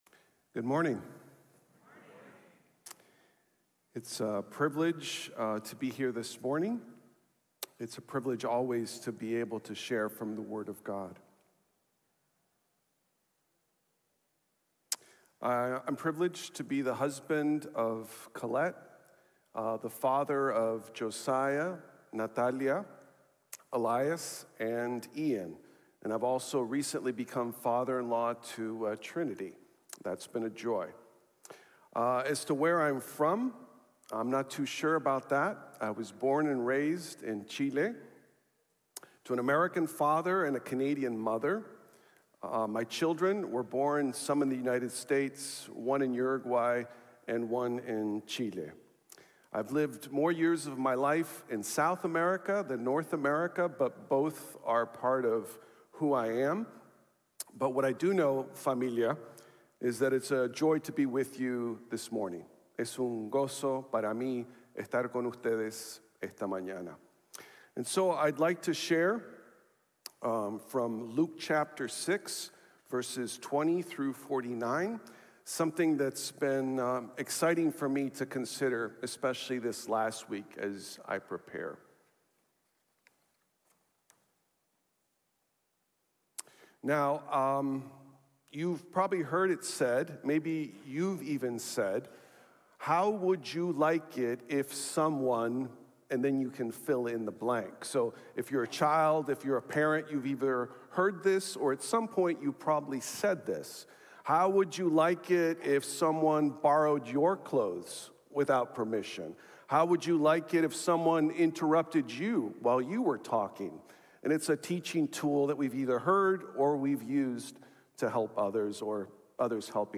Jesus Expects Mercy | Sermon | Grace Bible Church